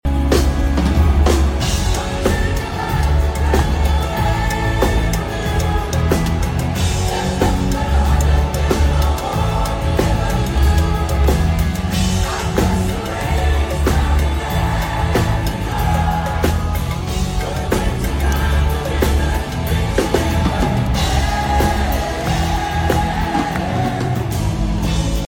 singing the chorus
tour together in the USA
#80smusic